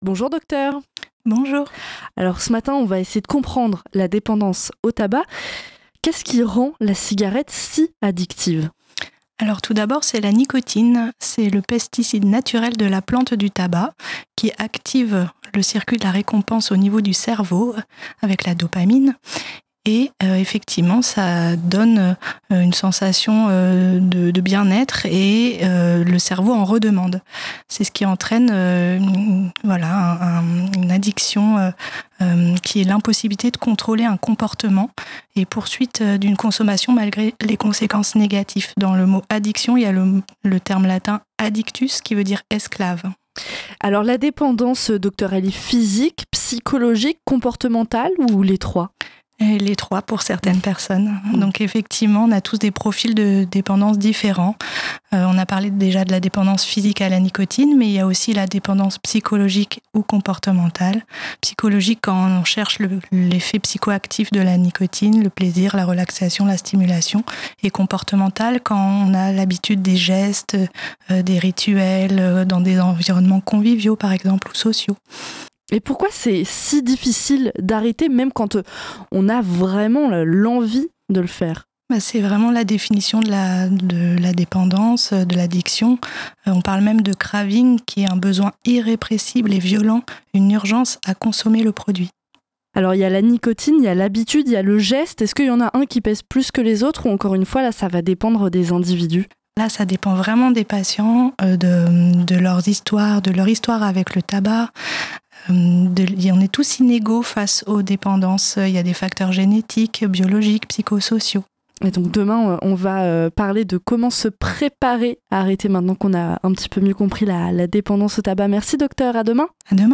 Diffusées à l’antenne et disponibles ici, ces chroniques sont là pour vous accompagner, vous encourager et vous rappeler que chaque effort compte.